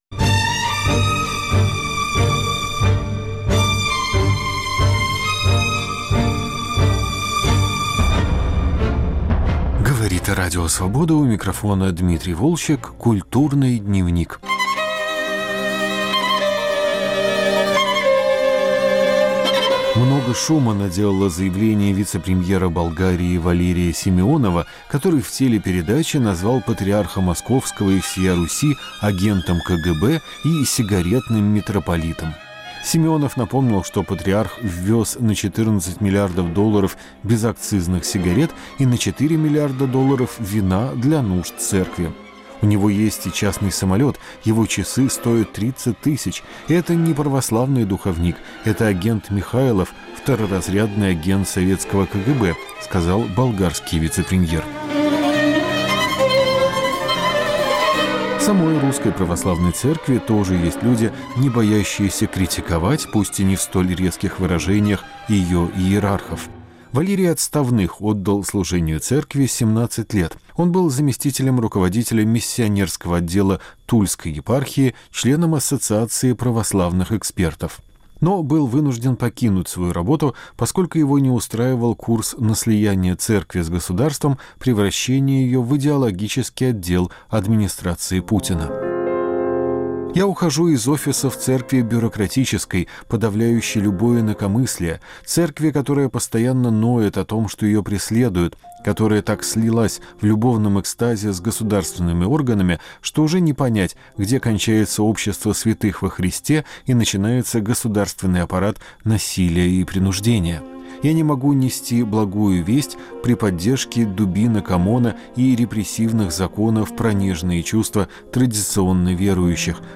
Операция "Дары волхвов": разговор с бывшим миссионером РПЦ +++ О книге Адама Надашди "Толстокожая мимоза"